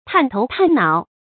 探頭探腦 注音： ㄊㄢˋ ㄊㄡˊ ㄊㄢˋ ㄣㄠˇ 讀音讀法： 意思解釋： 探：頭或上體向前伸出。